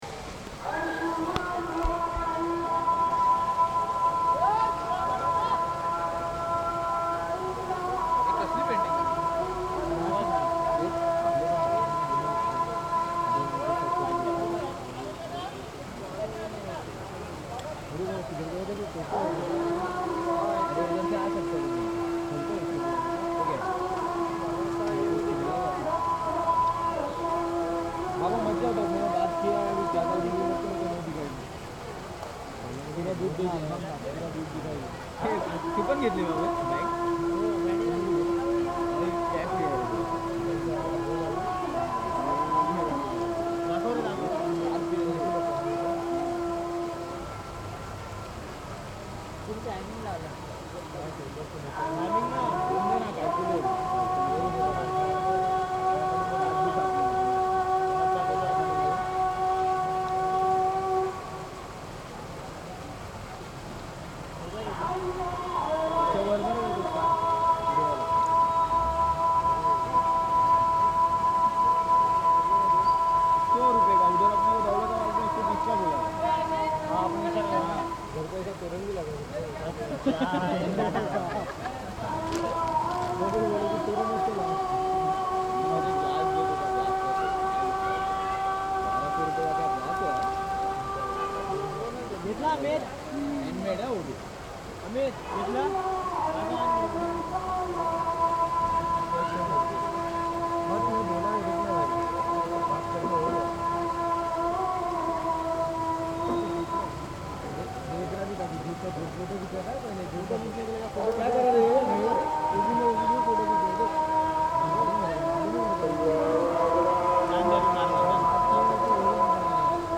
muesin.mp3